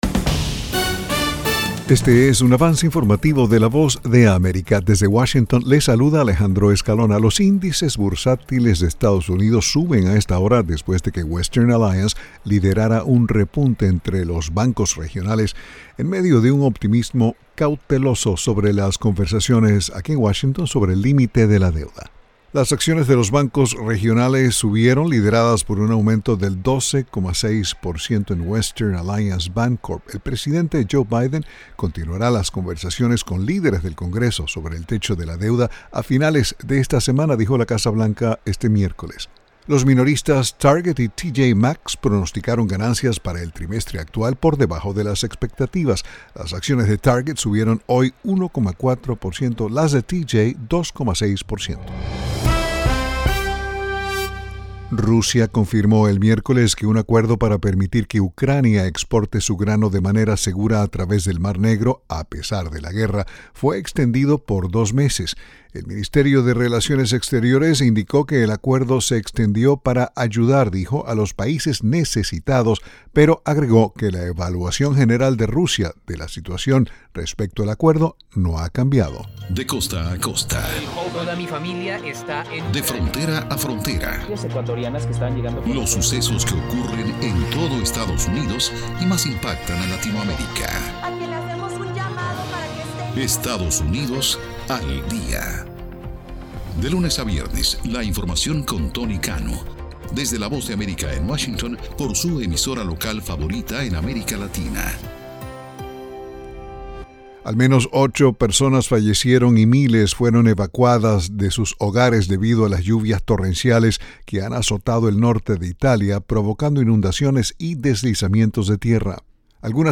Avance Informativo 1:00pm
Este es un avance informativo presentado por la Voz de América en Washington.